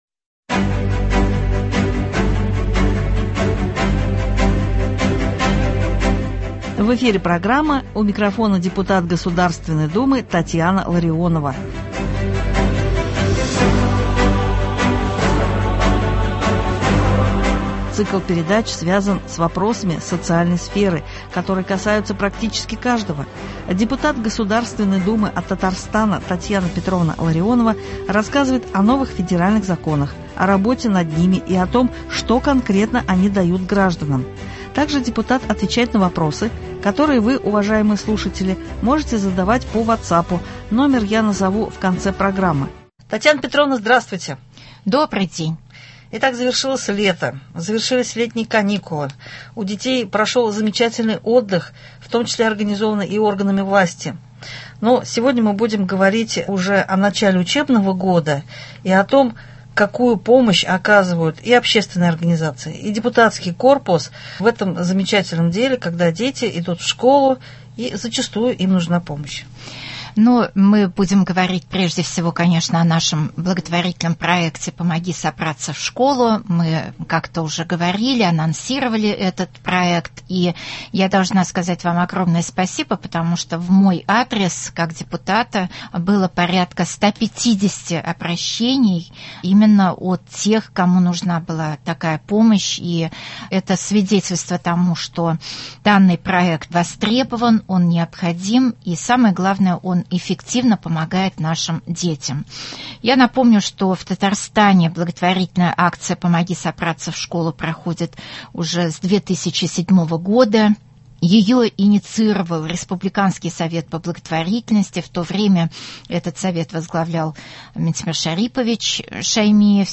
У микрофона депутат Татьяна Ларионова (07.09.23)